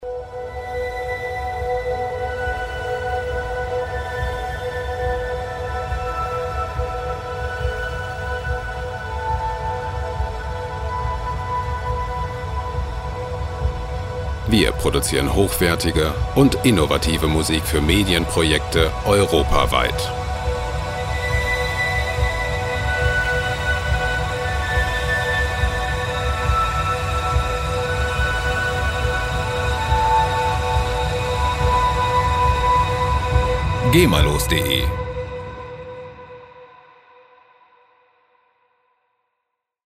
Ambient Loops gemafrei
Musikstil: Ambient
Tempo: 70 bpm